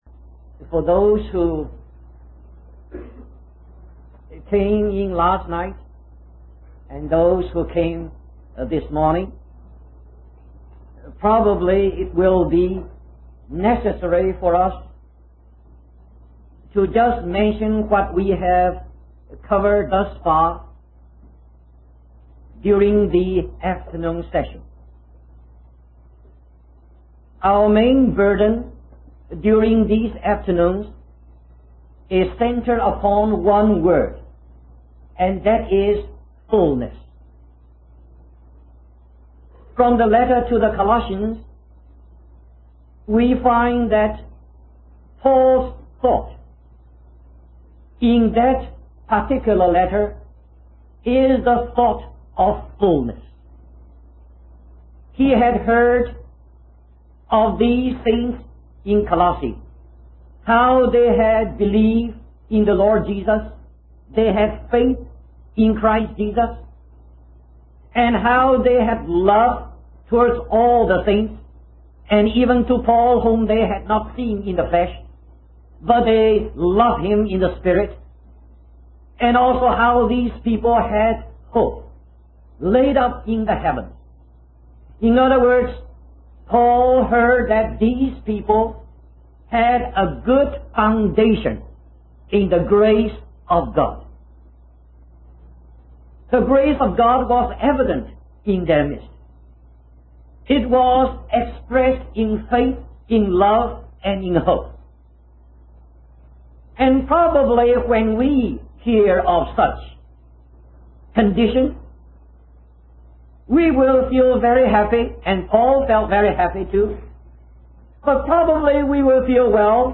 In this sermon, the preacher emphasizes the importance of believers putting on the characteristics of being chosen and holy by God.